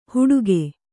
♪ huḍuge